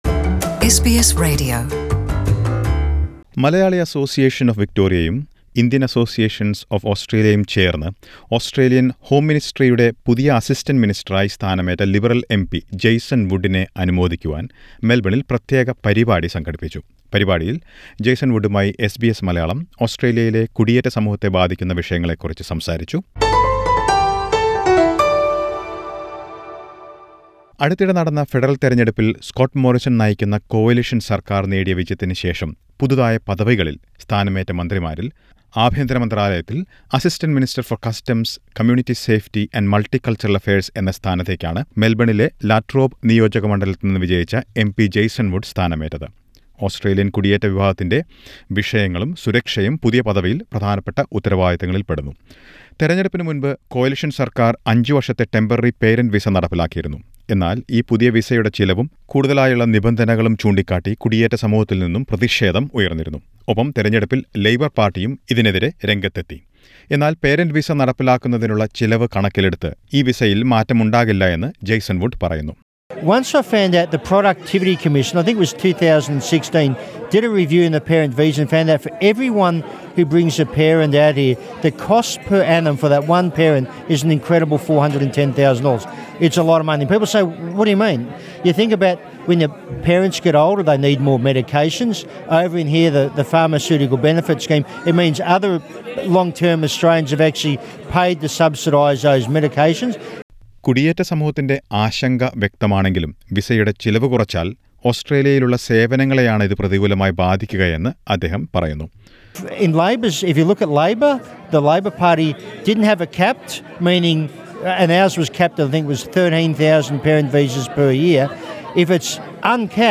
ഈ പരിപാടിക്കിടയിലാണ് എസ് ബി എസ് മലയാളവുമായി കുടിയേറ്റ സമൂഹത്തെ ബാധിക്കുന്ന വിഷയങ്ങളെക്കുറിച്ച് അദ്ദേഹം സംസാരിച്ചത്.